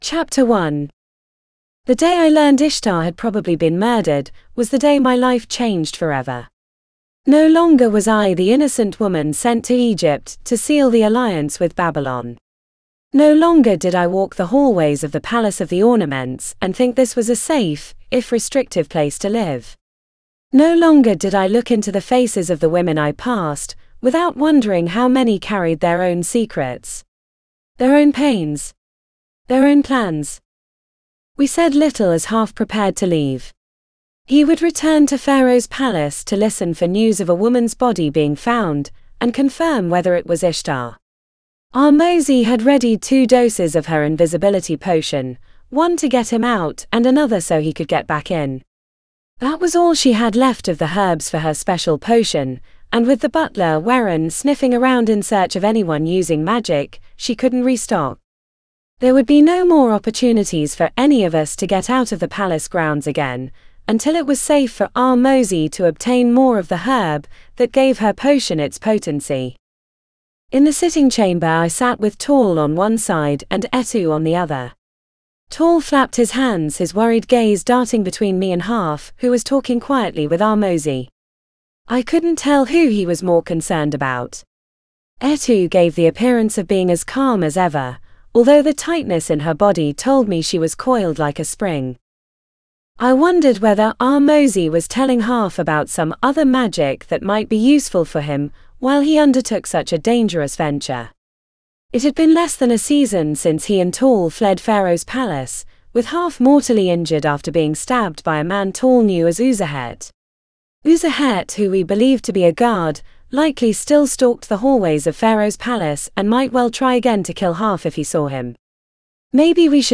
Narrator: This audiobook is digitally narrated using the voice of Anya and produced by Google Play.